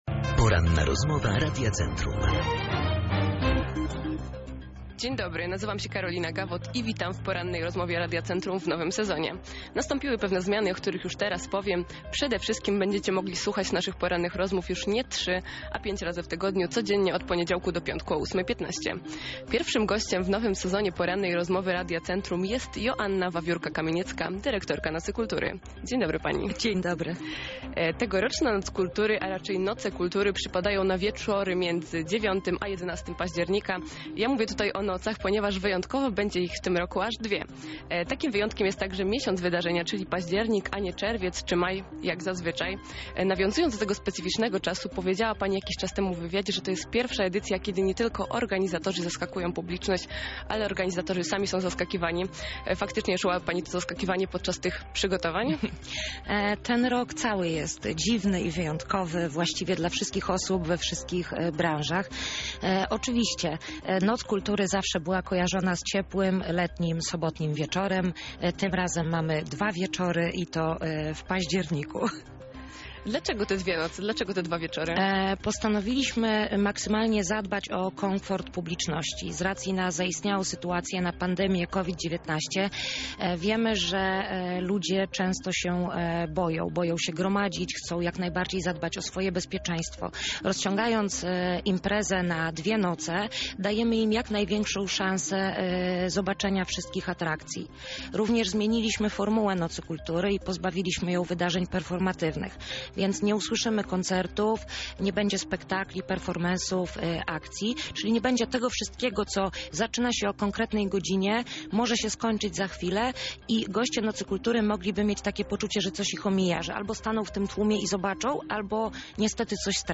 Jeden z punktów programu zakłada, że organizatorzy nie informują publiczności, w której części Starego Miasta znajduje się instalacja artystyczna. O tym, jakie jeszcze zmiany niesie ze sobą tegoroczna Noc Kultury usłyszycie w rozmowie poniżej: